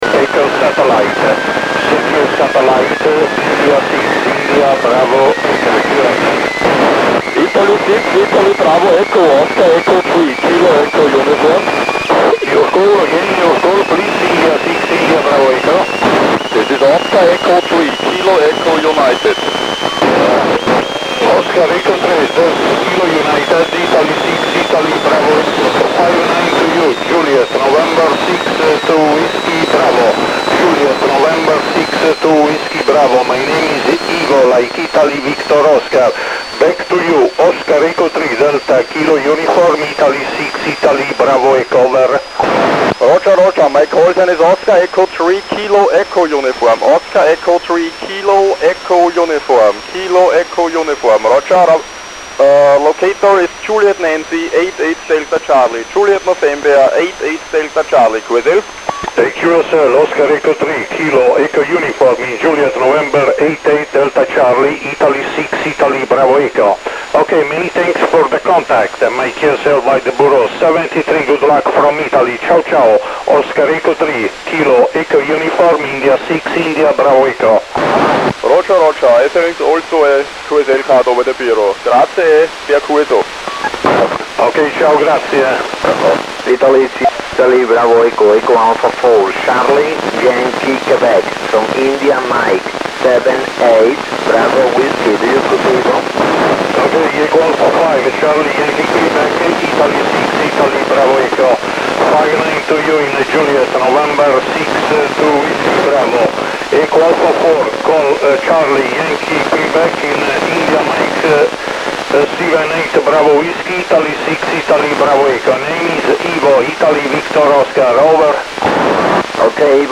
Registrazioni AUDIO MP3 del nostro primo qso via AO-51 (Oscar 51) , SO-50, FO-29, VO-52